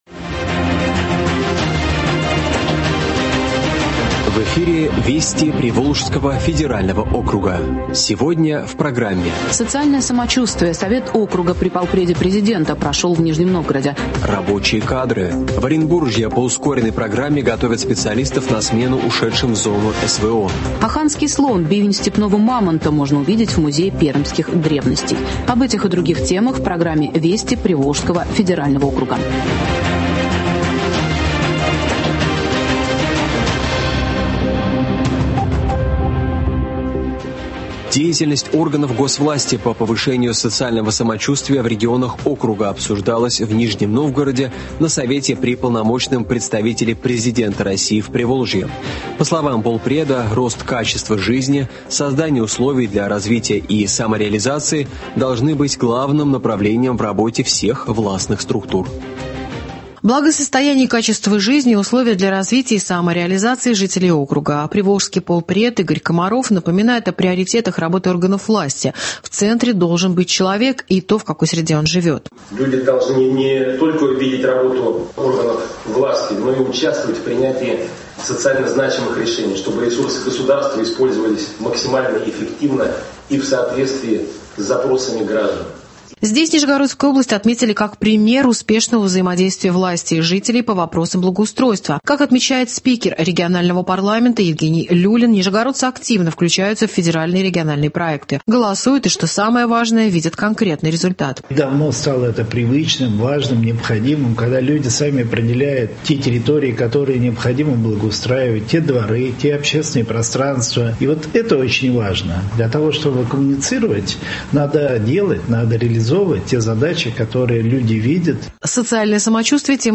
Радио обзор событий недели в регионах ПФО.